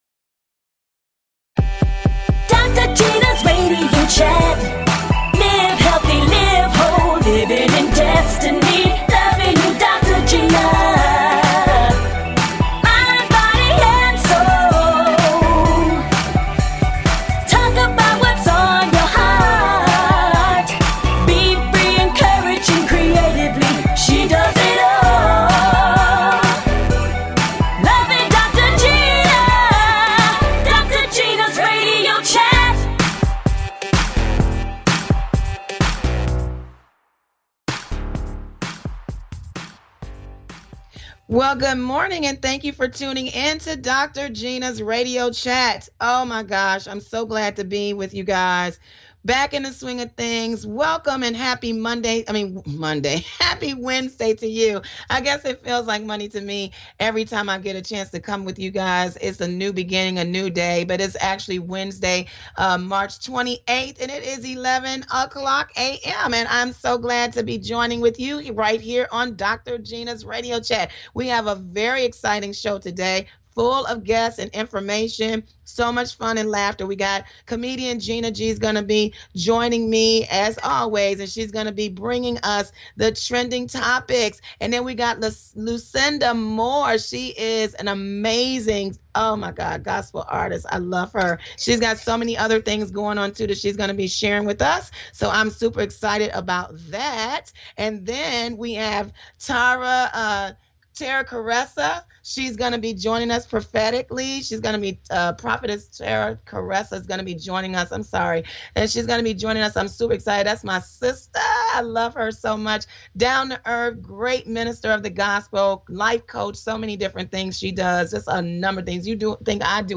Talk Show
And full of laughter!